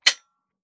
12ga Pump Shotgun - Dry Trigger 003.wav